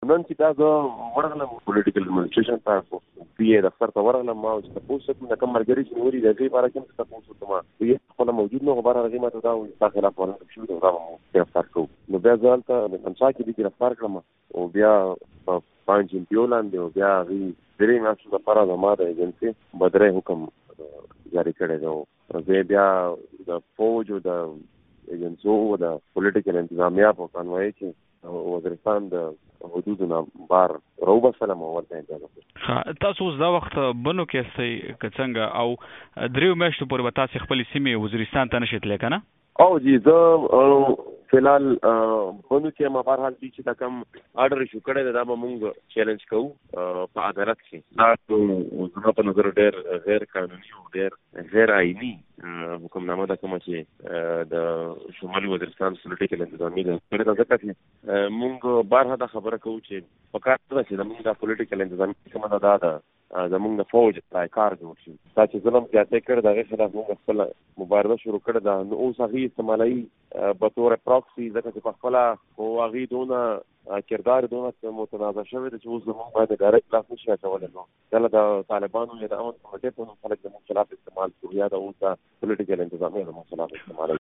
ده د جون پر اوومه له بنو څخه په ټېلېفون مشال راډیو ته وویل چې د چارشنبې یا شورو پر ورځ یې په میرلي کې د هدفي وژنو ضد لاریون کړی وو چې ورباندې امنیتي ځواکونو چاپه ووهله او د مظاهرې پر ګډونوالو یې تشدد وکړ.
له محسن داوړ سره بشپړه مرکه